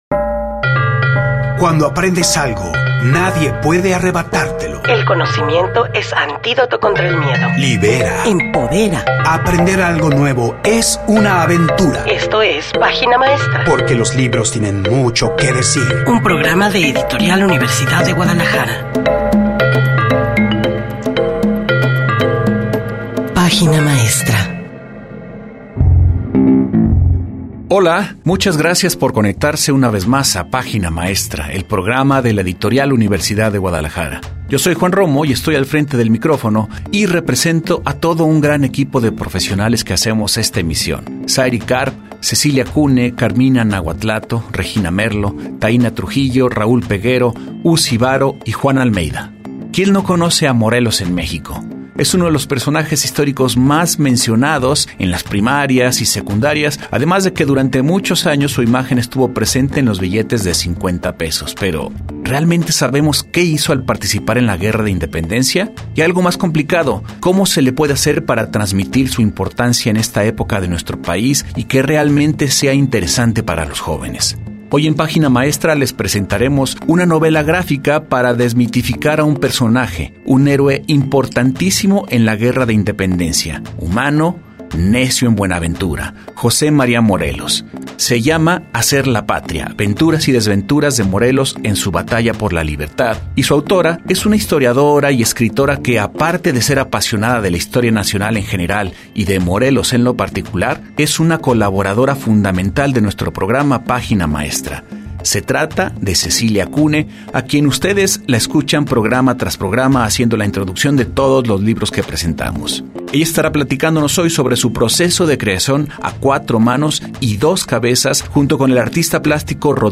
Escucha la retransmisión del episodio 116 en Radio Universidad de Guadalajara |Viernes 3 de abril | 11:00 am